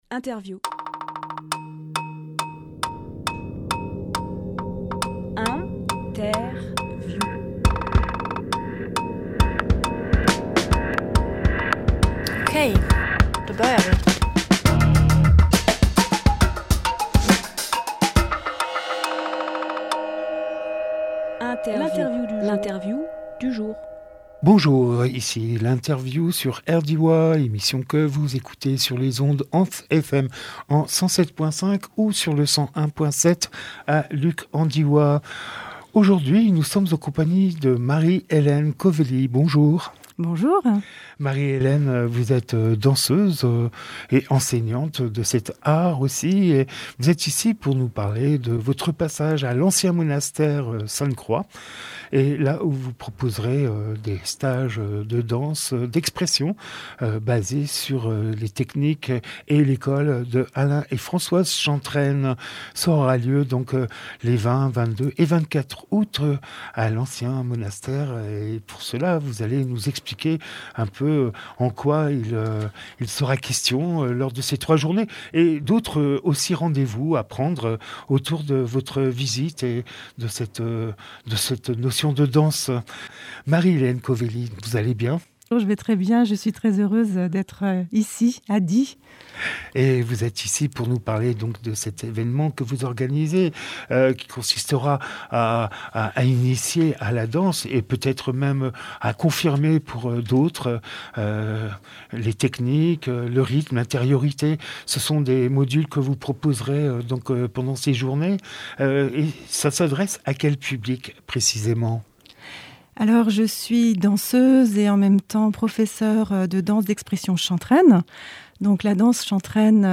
Emission - Interview De la Danse Chantraine à Sainte-Croix Publié le 17 août 2023 Partager sur…
16.08.23 Lieu : Studio RDWA Durée